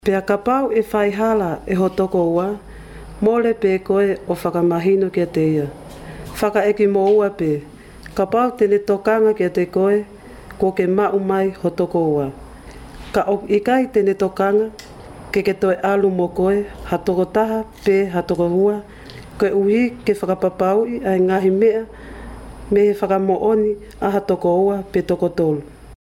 Female Professional Voice Over Talent | VoicesNow Voiceover Actors
Voice overs produced by US and international actors.